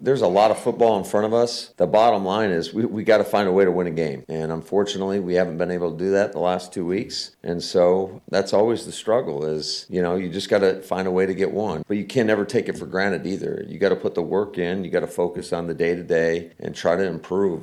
(LEARFIELD) – Green Bay Packers coach Matt LaFleur covered a lot of topics when he spoke with the media on Thursday.
LaFleur talked about trying to end the 2-game slide.